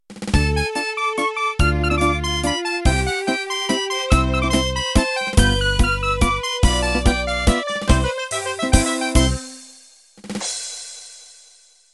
Second of a pair of Italian Waltzes.